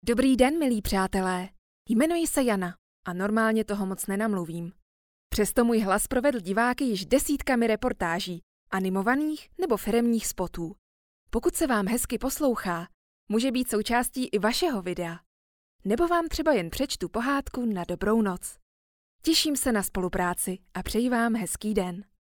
Profesionální český ženský voiceover
profesionální zvuk a vysoká technická kvalita